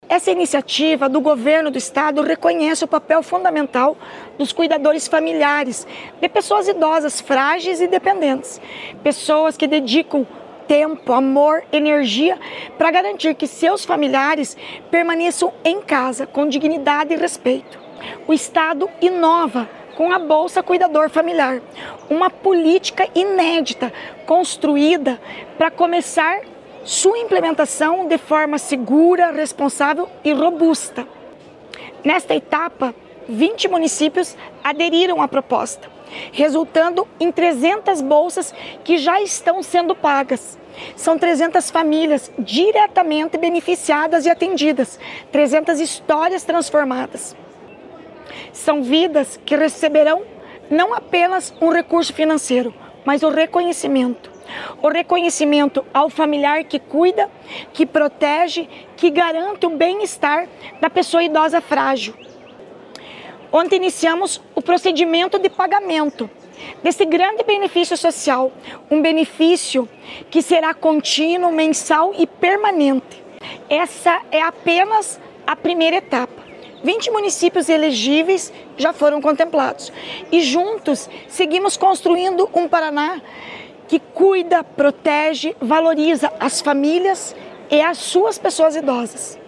Sonora da secretária da Mulher, Igualdade Racial e Pessoa Idosa, Leandre Dal Ponte, sobre os primeiros pagamentos do Bolsa Cuidador Familiar